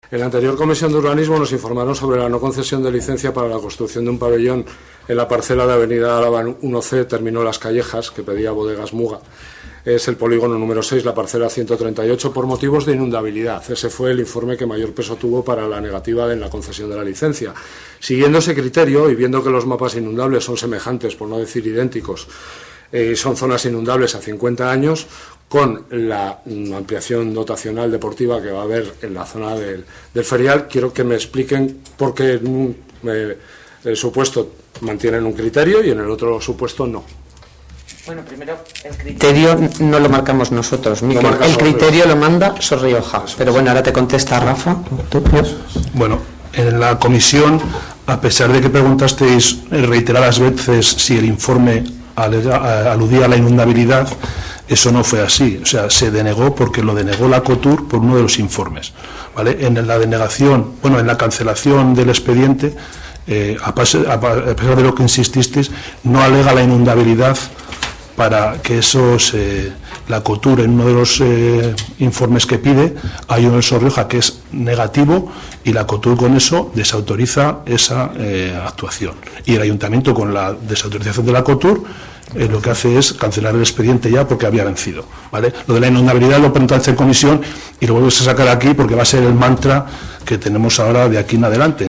Es la respuesta del primer teniente de alcalde del Ayuntamiento de Haro, Rafael García, a la pregunta que hizo desde la bancada socialista Miguel García Urquidi en el último pleno municipal.